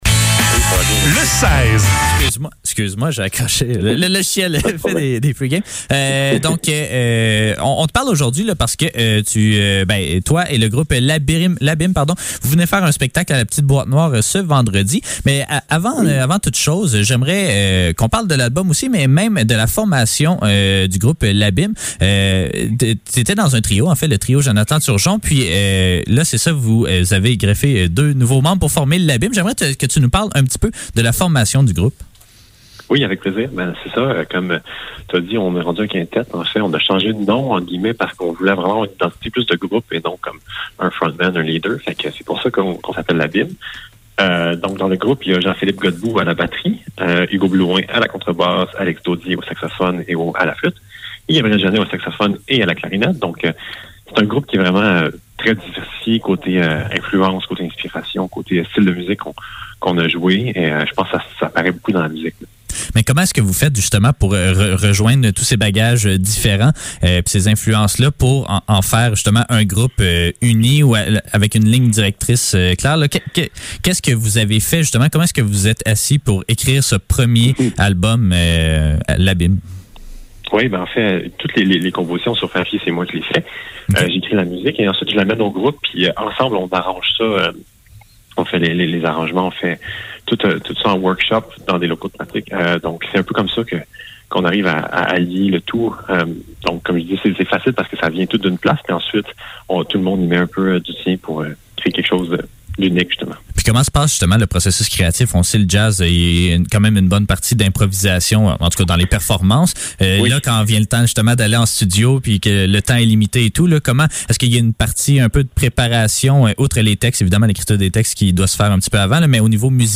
Le seize - Entrevue avec L'Abîme - 15 décembre 2021